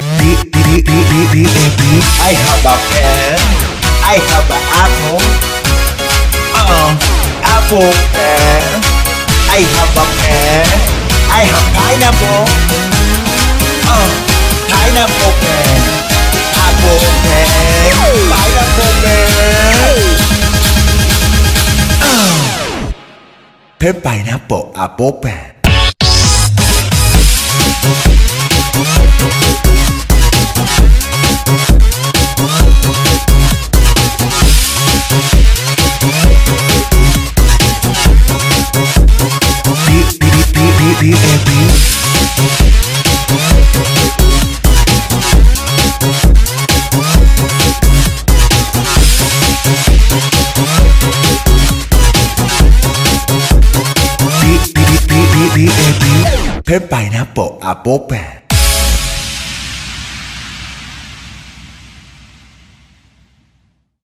• Качество: 320, Stereo
ритмичные
remix
веселые
заводные
Electronic
electro